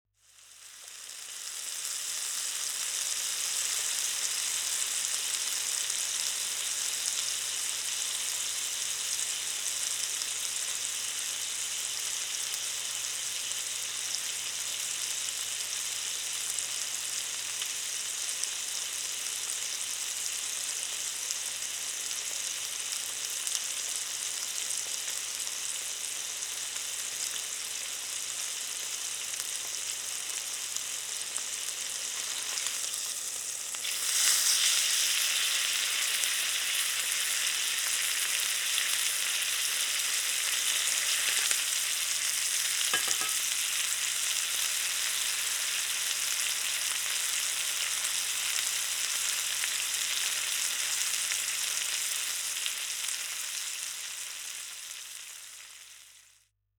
Food Sizzling on the BBQ
Steak-Sizzling.mp3